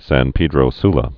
(săn pēdrō slə, sän pĕdrō slä)